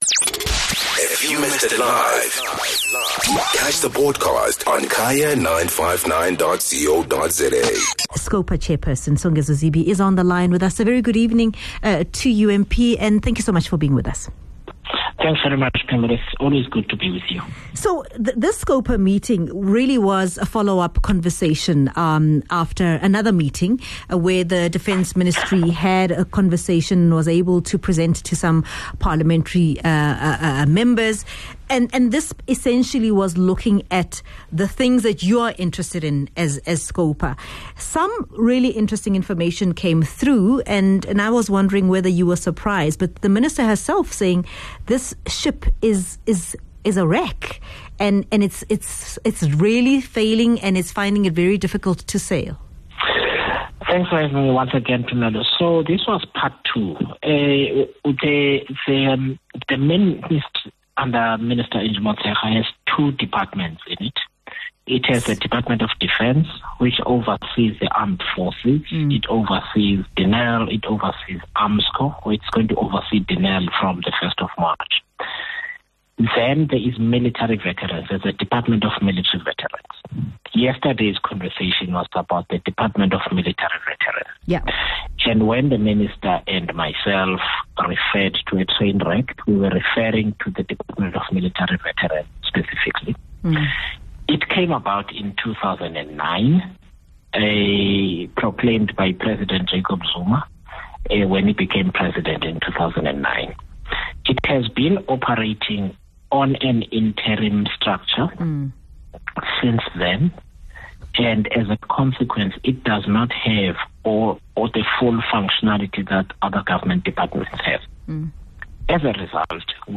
speaks to Chairperson of the Standing Committee on Public Accounts, Songezo Zibi.